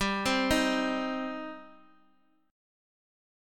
Listen to Gsus4#5 strummed